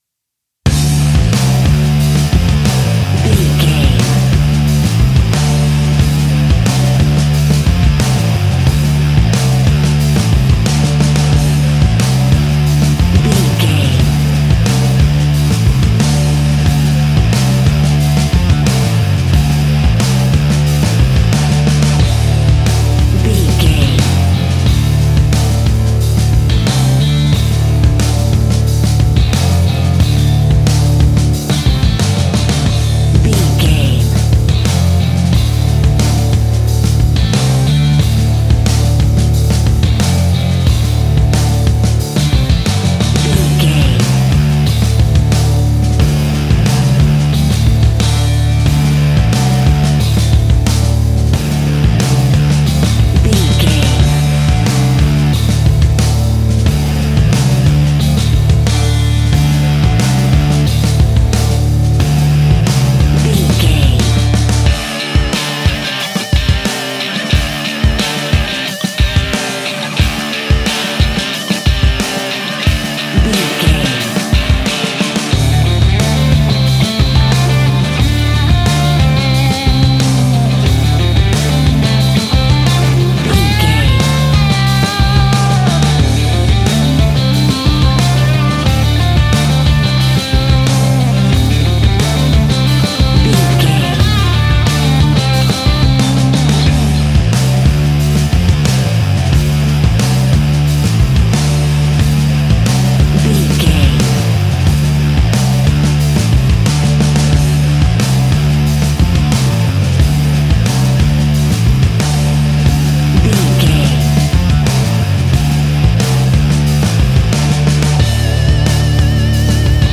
Ionian/Major
D
energetic
driving
heavy
aggressive
electric guitar
bass guitar
drums
hard rock
blues rock
distorted guitars
hammond organ